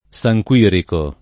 Saj kU&riko] top.